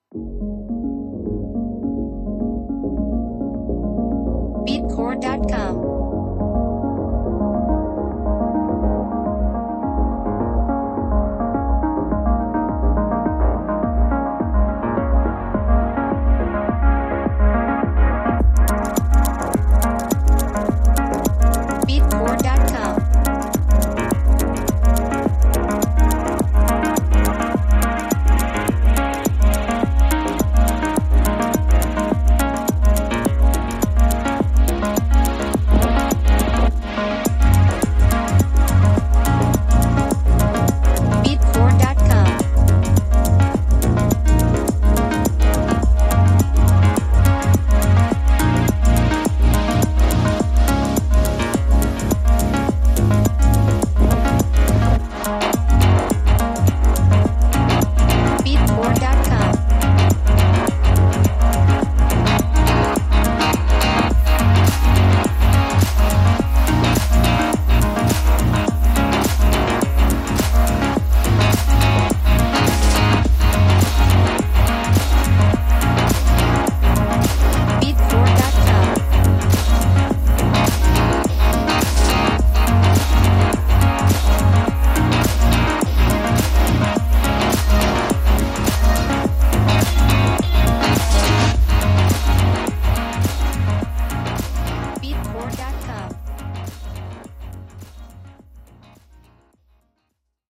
Instruments: Synthesizer